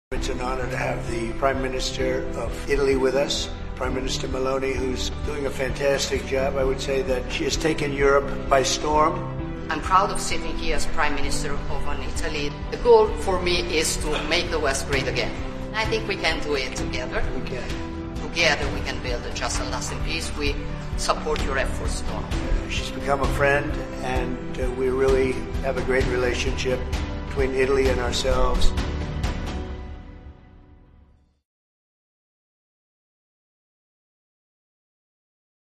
President Trump Italian PM Meloni at the White House Apr. 17 2025 | The White House